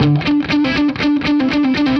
Index of /musicradar/80s-heat-samples/120bpm
AM_HeroGuitar_120-D01.wav